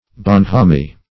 Search Result for " bonhommie" : The Collaborative International Dictionary of English v.0.48: Bonhomie \Bon`ho*mie"\, Bonhommie \Bon`hom*mie"\ (b[o^]n`[o^]*m[=e]"), n. [F.]